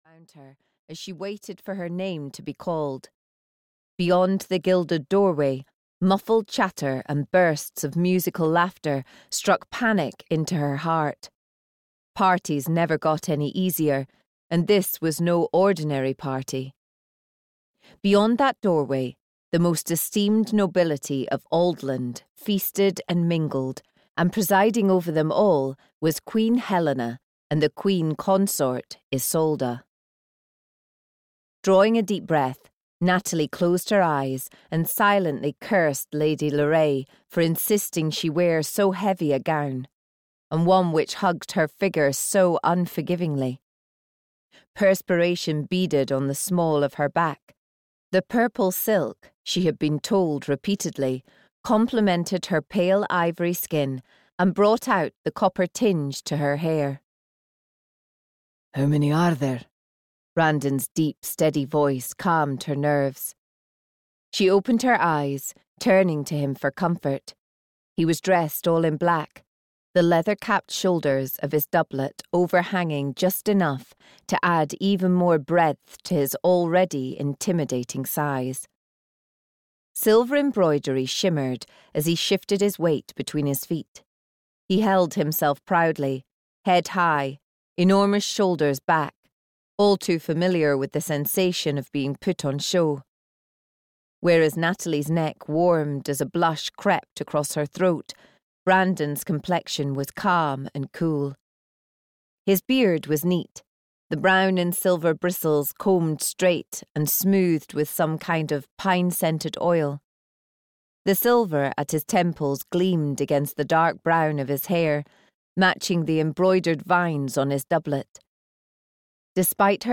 Forever His Champion (EN) audiokniha
Ukázka z knihy